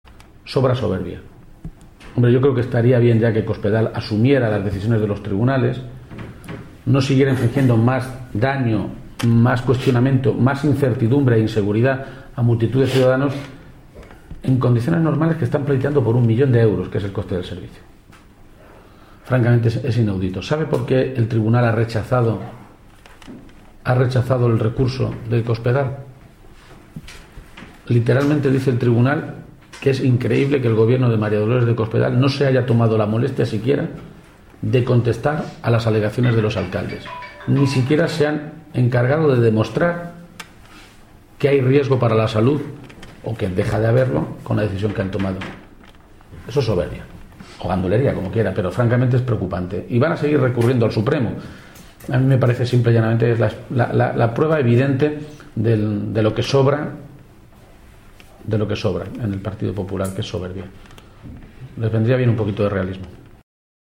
García-Page se pronunciaba de esta manera en una rueda de prensa en la sede provincial del PSOE de Jaén, ciudad a la que ha acudido invitado para participar en una charla-coloquio sobre los retos de la política actual y en la que va a hablar, de manera específica, de los modelos políticos regionales de Andalucía y Castilla-La Mancha.